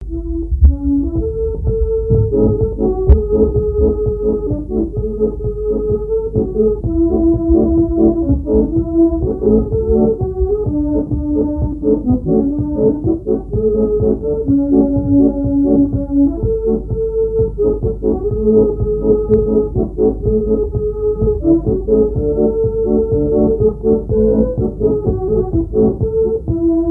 danse : marche
Répertoire à l'accordéon diatonique
Pièce musicale inédite